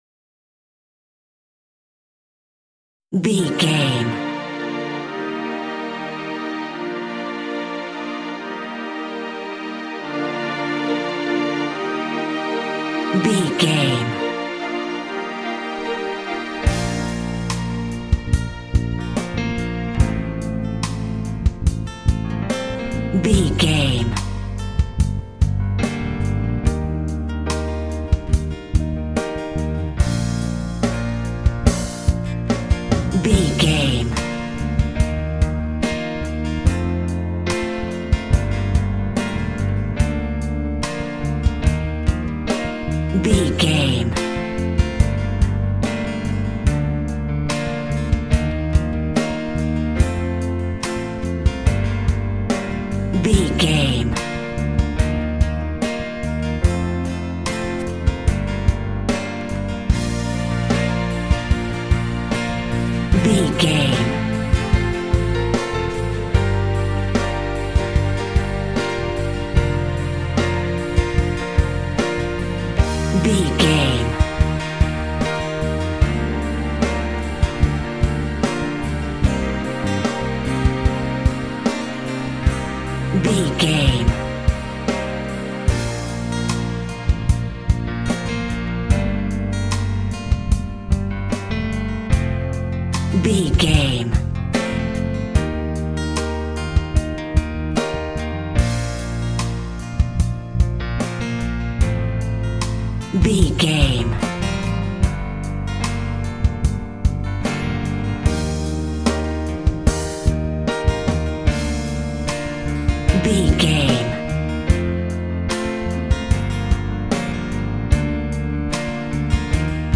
Love Ballad From the 80s.
Ionian/Major
B♭
cheesy
pop rock
synth pop
drums
bass guitar
electric guitar
piano
hammond organ